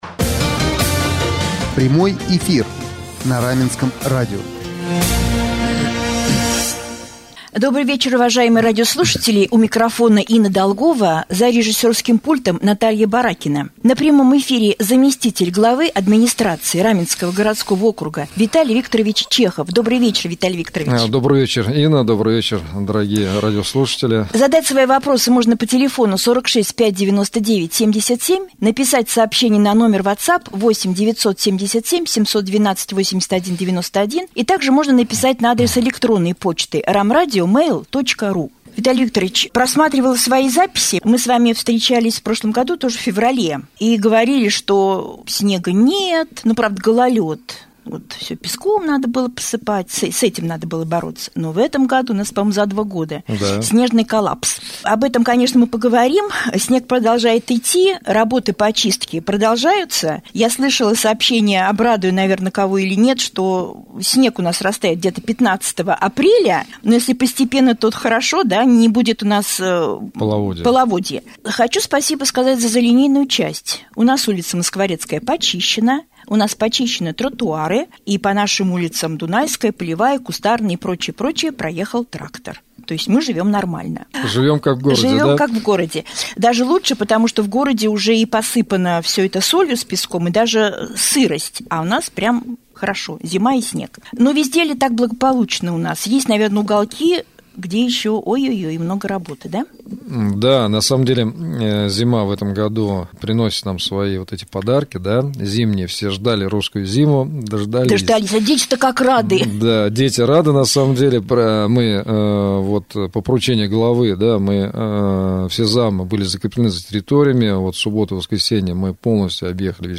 В среду, 17 февраля, гостем прямого эфира стал заместитель главы администрации Раменского г.о. Виталий Чехов.